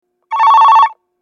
２番線発車ベル